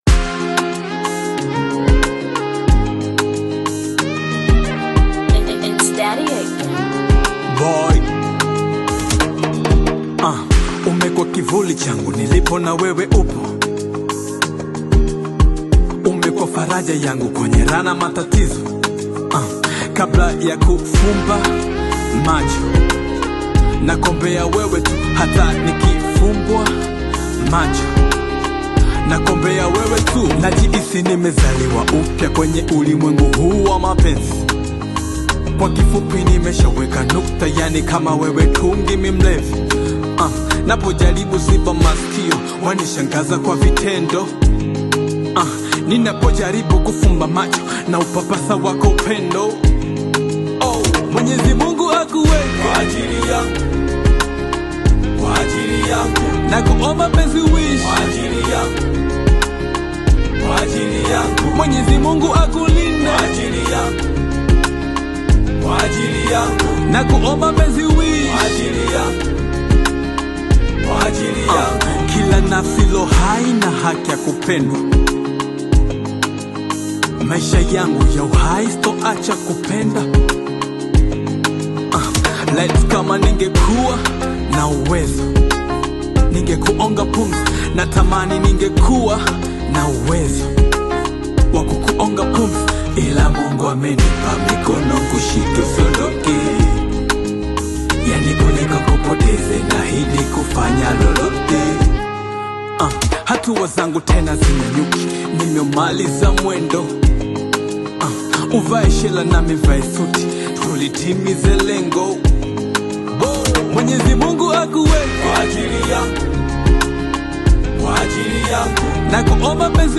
Tanzanian Bongo Flava artist, singer, rapper and songwriter
a love song
vocals evoke emotion and sincerity
African Music